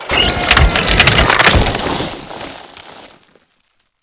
iceunload.wav